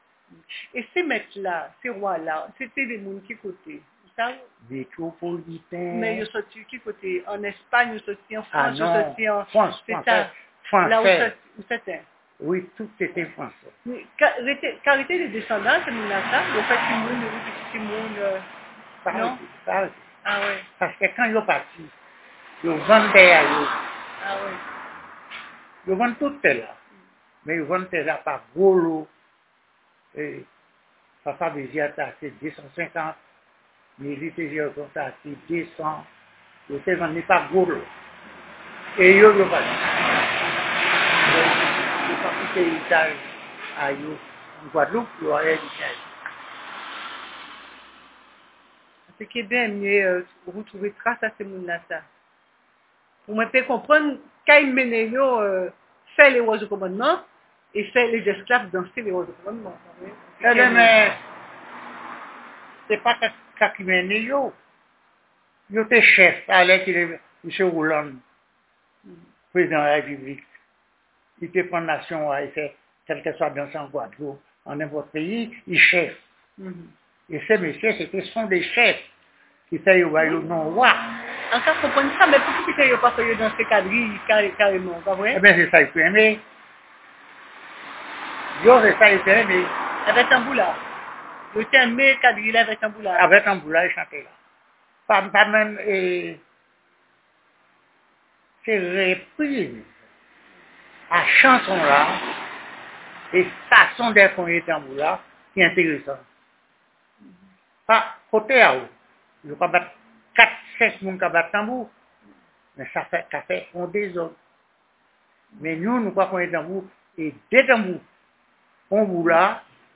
Interview audio Laméca
© Médiathèque Caraïbe (Laméca) Une interview du programme de collecte de la mémoire orale des musiques en Guadeloupe ( fonds Palé pou sonjé ), mené par Laméca depuis 2005.